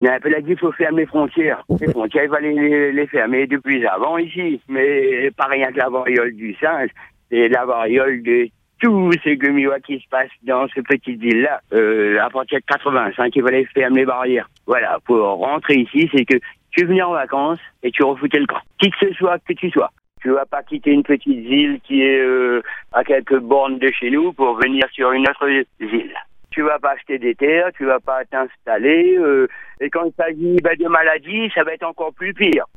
Un auditeur, particulièrement alarmé, a décidé de prendre la parole sans langue de bois. Pour lui, les autorités ne vont pas assez loin et tardent à prendre des mesures fortes face à un risque qu’il estime imminent.
Fermeture des frontières, contrôles renforcés, restrictions de circulation : ses propos sont directs et tranchés.